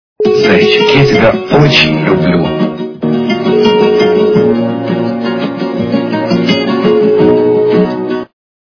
Гавайская гитара и мужской голос - Зайчик, я тебя очень люблю! Звук Звуки Гавайська гітара і чоловічий голос - Зайчик, я тебя очень люблю!
При прослушивании Гавайская гитара и мужской голос - Зайчик, я тебя очень люблю! качество понижено и присутствуют гудки.